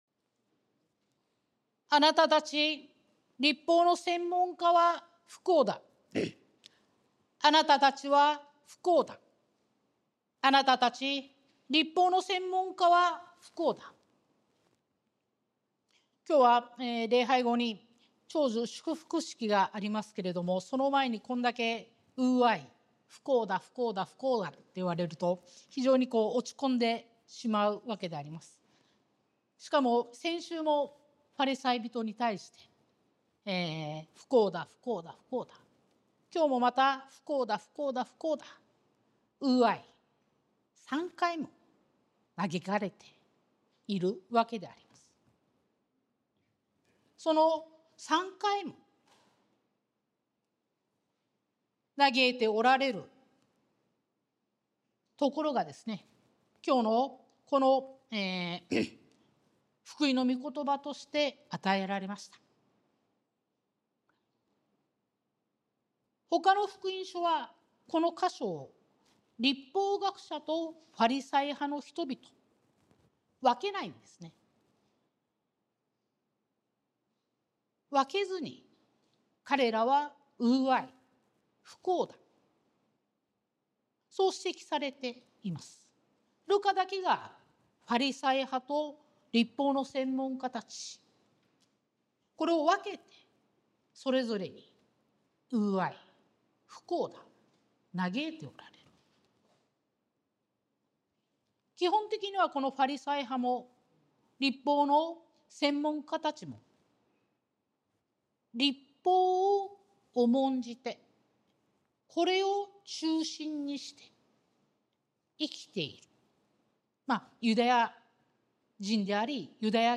sermon-2024-09-15